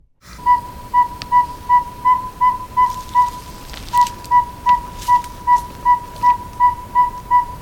Northern-Saw-whet Owl
(Aegolius acadicus)
Northern-Saw-whet-Owl-dit.mp3